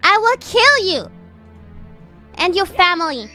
Worms speechbanks
Illgetyou.wav